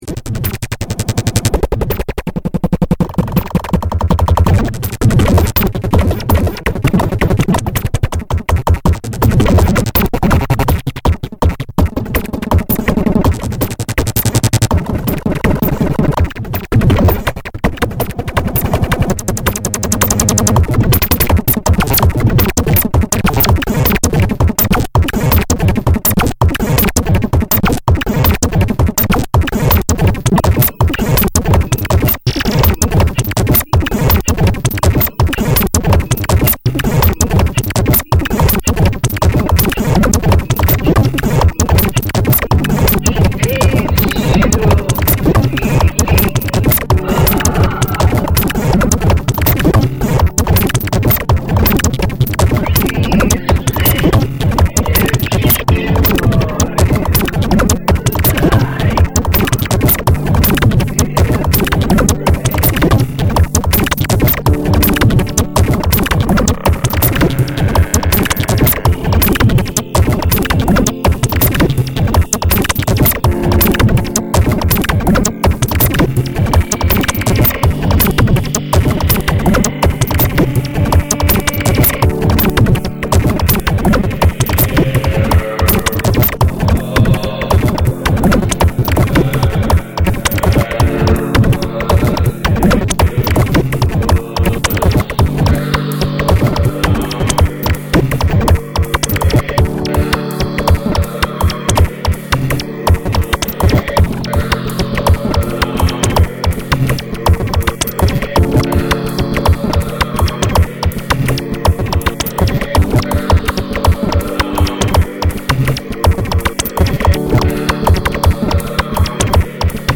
構築の逆のような八つ裂きの音がPANされてて、脳みそをつんざきますよ。